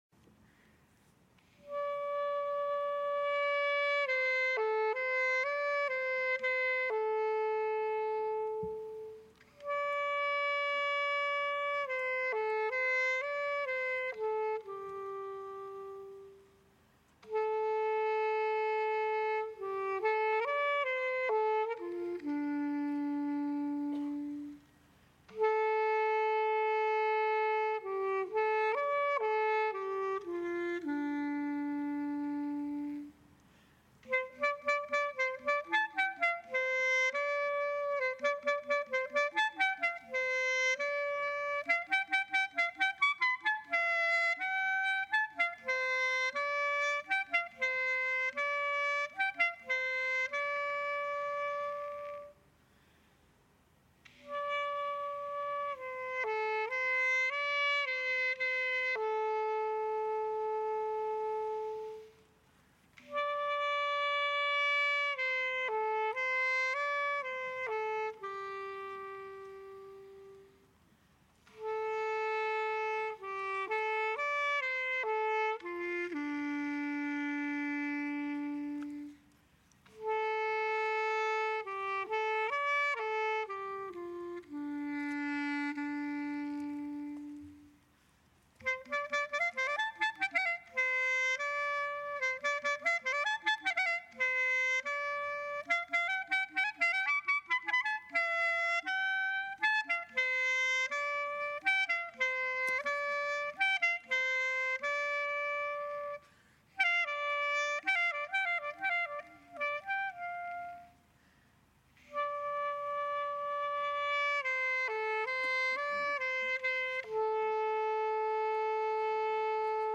Erster Spatenstich – Seite 2 | Margit Horváth Stiftung
Klarinette